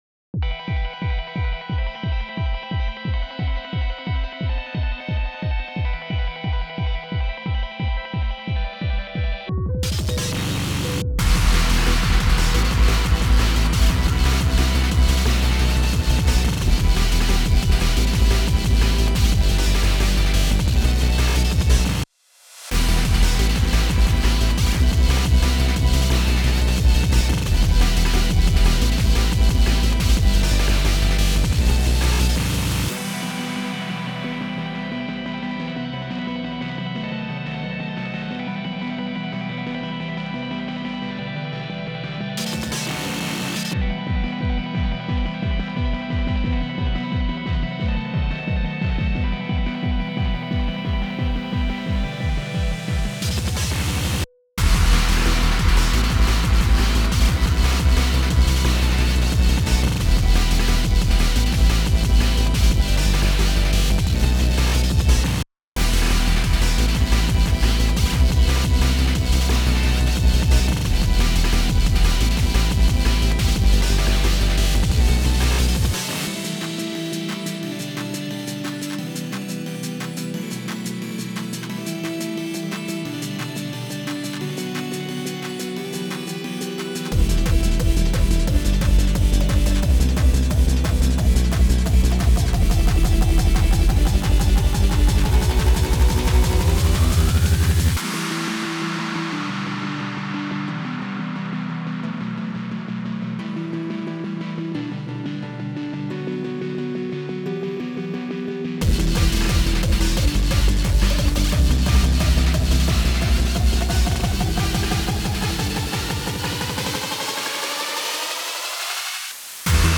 オフボーカルを上げるのを忘れてしまっていました！
INST